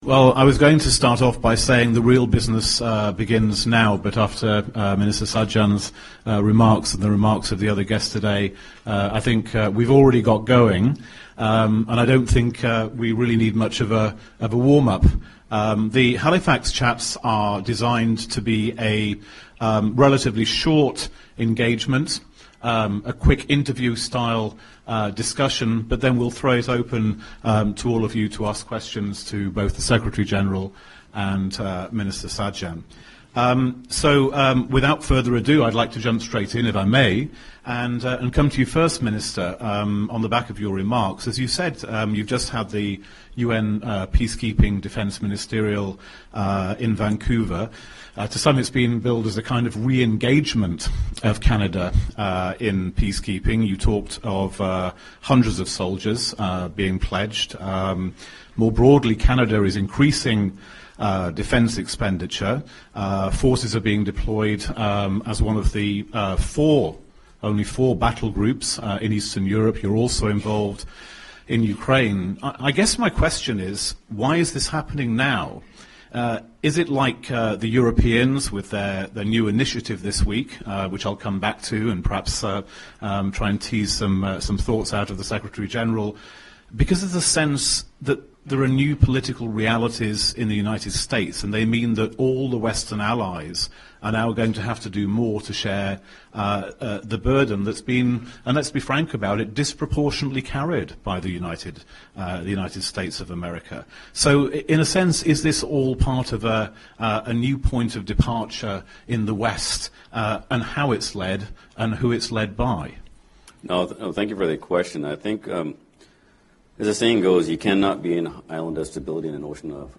Secretary General Jens Stoltenberg accepted the Halifax Builder Award on behalf of NATO in the margins of the Halifax International Security Forum on Friday (17 November 2017). The award is presented annually to individuals or organisations which have made an outstanding contribution to strengthening cooperation amongst democracies.